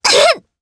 Frey-Vox_Damage_jp_02.wav